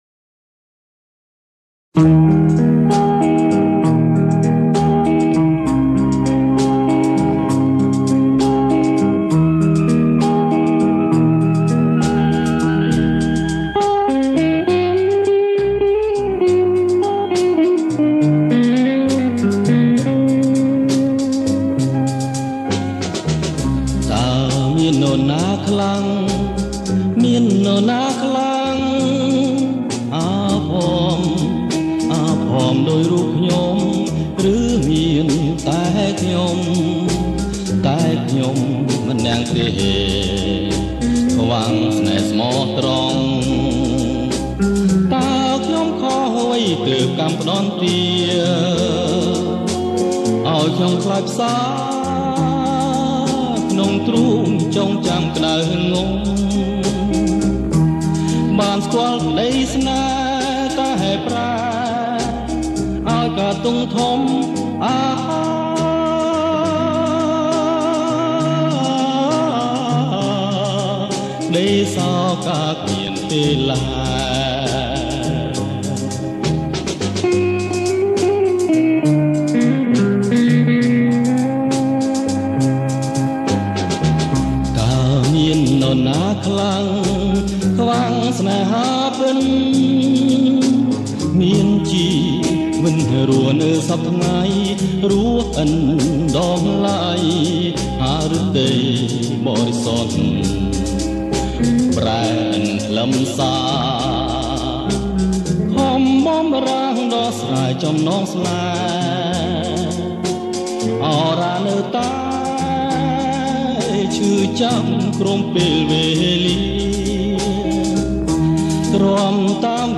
• ប្រគំជាចង្វាក់ Slow Rock